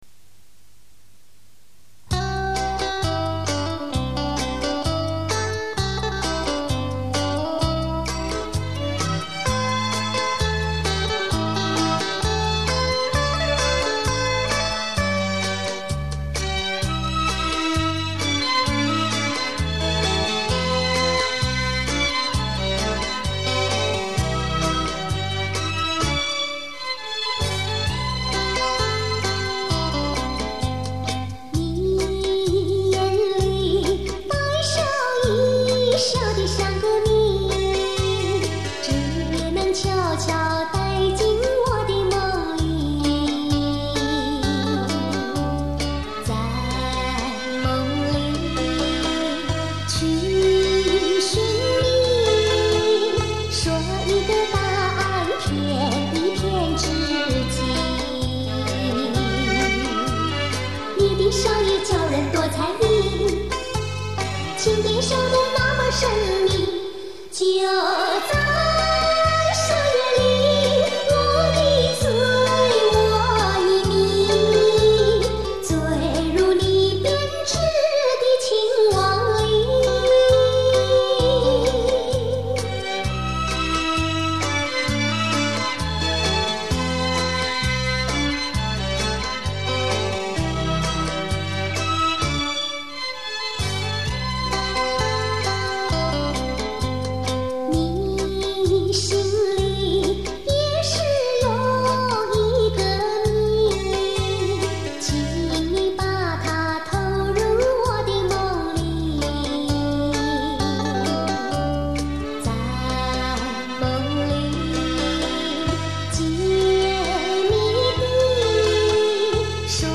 她演唱的歌曲脍炙人口，声音甜美清澈，深受歌迷喜爱。